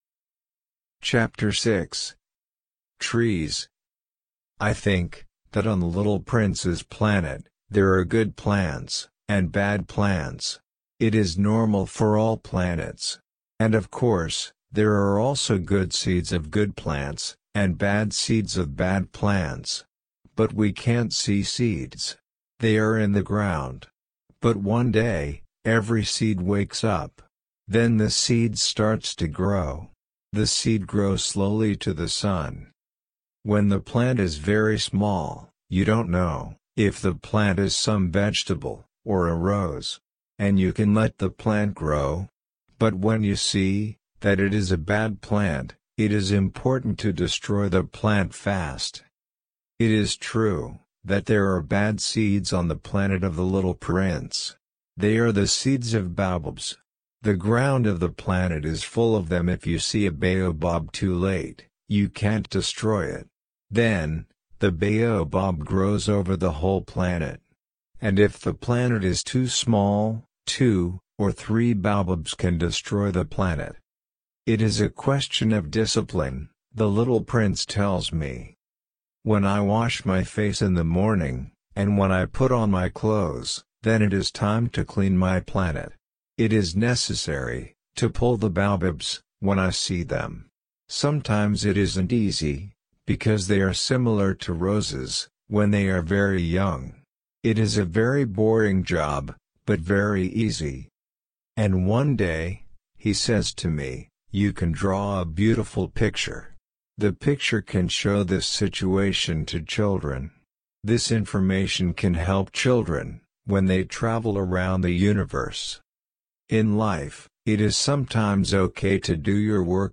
LP-L1-Ch6-slow.mp3